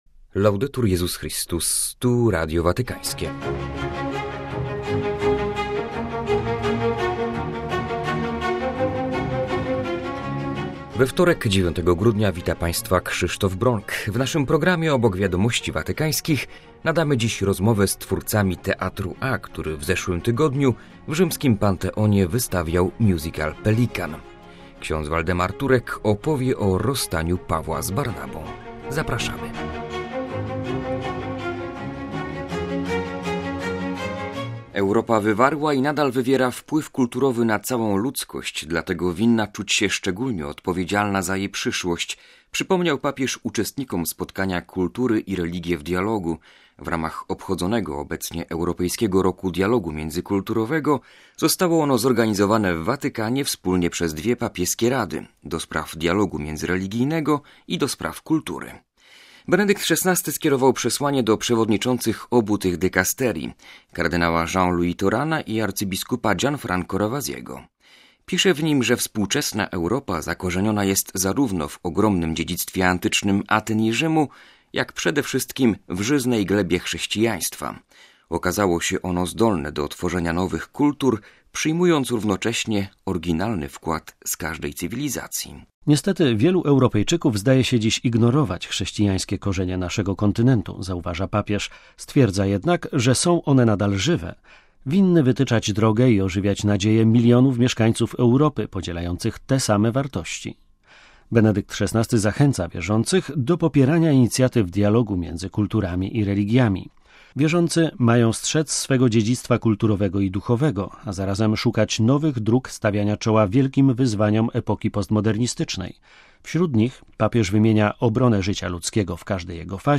rozmowa z twórcami Religijnego Teatru A z Gliwic